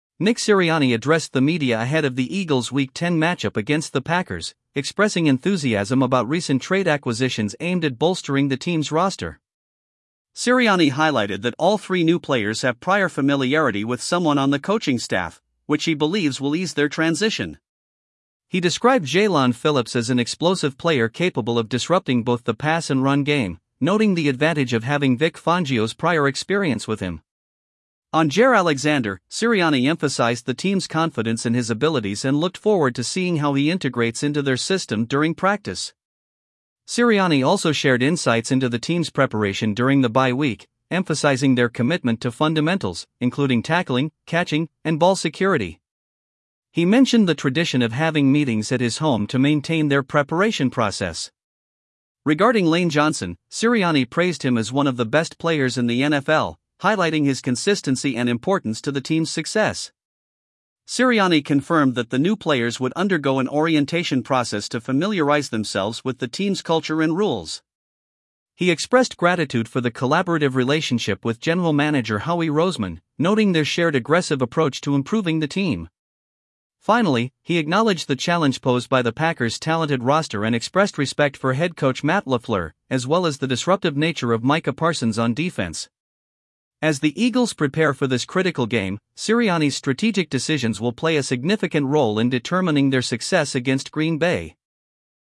Nick Sirianni addressed the media ahead of the Eagles' Week 10 matchup against the Packers, expressing enthusiasm about recent trade acquisitions aimed at bolstering the team's roster.